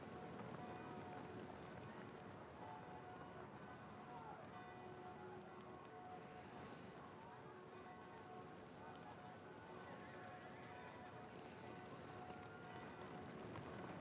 sfx_amb_map_settlement_burning.ogg